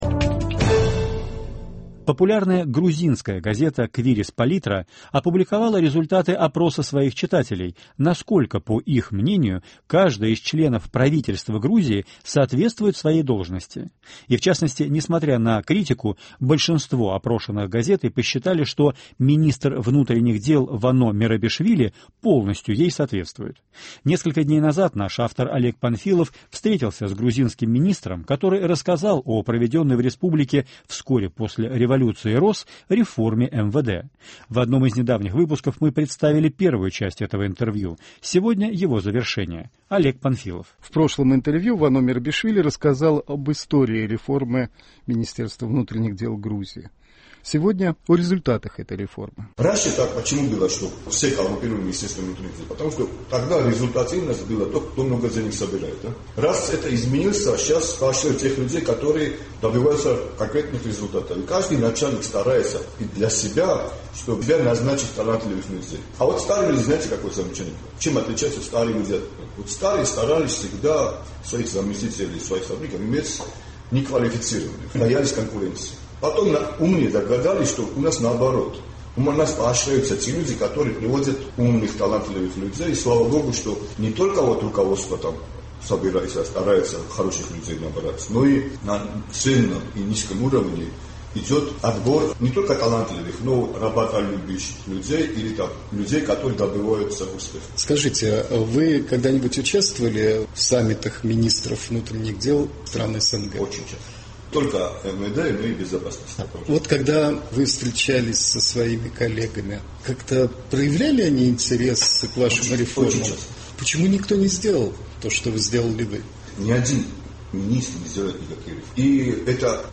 Цикл «Лица новой Грузии». Завершение интервью с руководителем МВД Грузии.